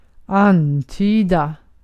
Ääntäminen
IPA : /ˈɪn.tɪ.mət/